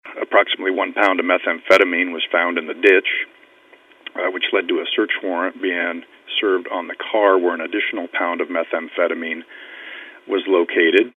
That wasn’t the end of it, according to Marshall County Sheriff Steve Hoffman.